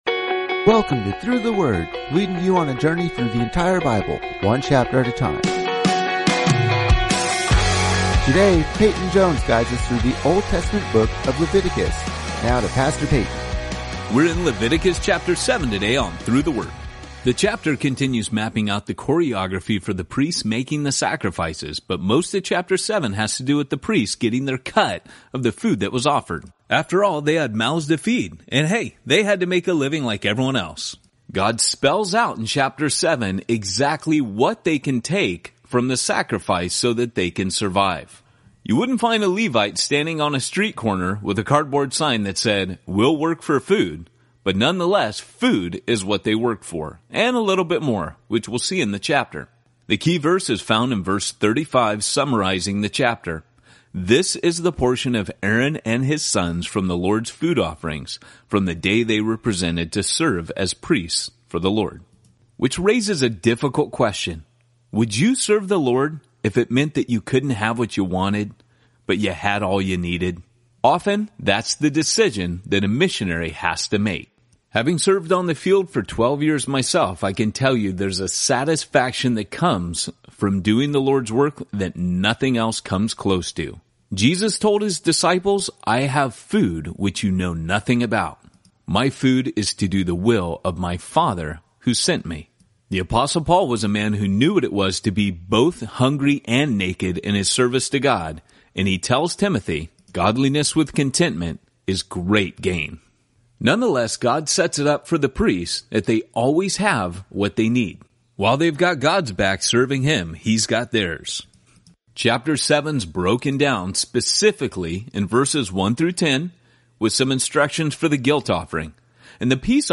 19 Journeys is a daily audio guide to the entire Bible, one chapter at a time. Each journey takes you on an epic adventure through several Bible books as your favorite pastors explain each chapter in under ten minutes.